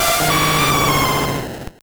Cri de Lugia dans Pokémon Or et Argent.